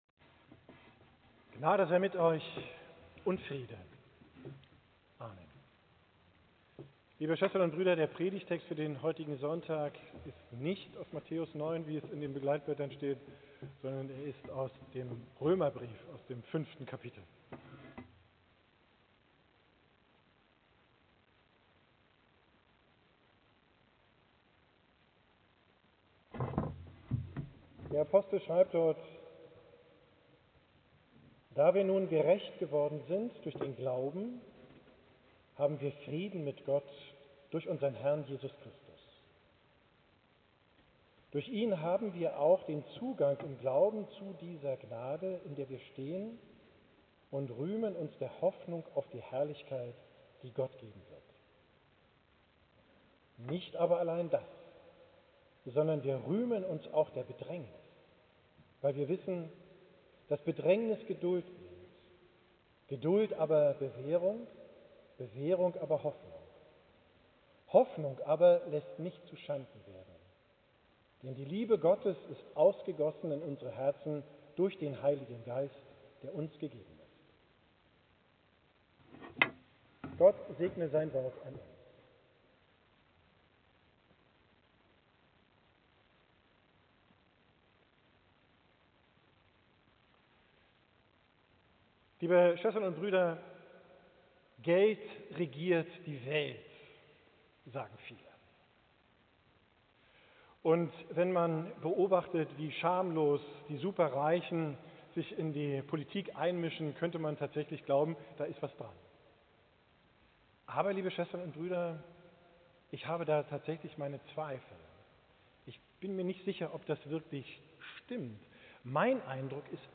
Predidgt vom Sonntag Reminiszere